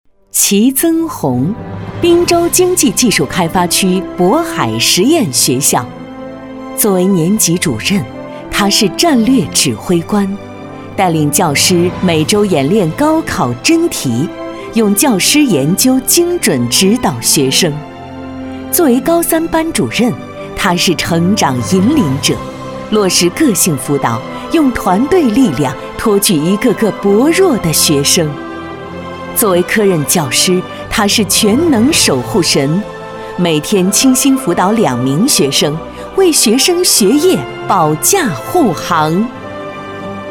颁奖
【颁奖】年会颁奖-男C4